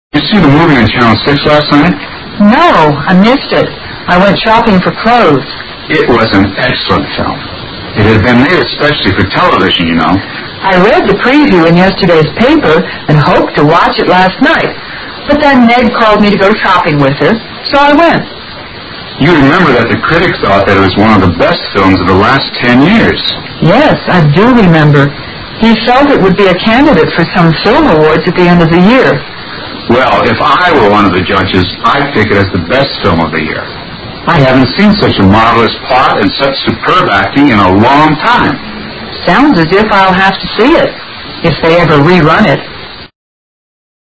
英语对话听力mp3下载Listen 28:TALKING ABOUT A FILM